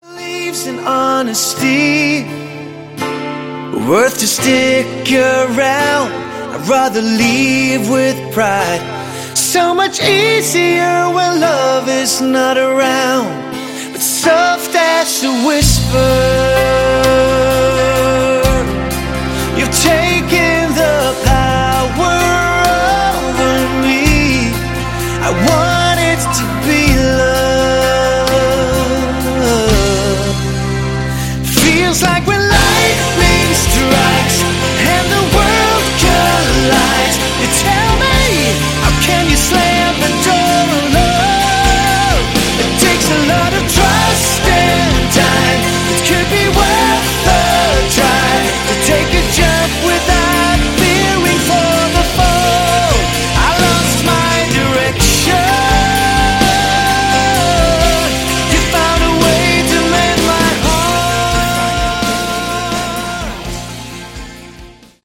Category: AOR
vocals, keyboards
guitars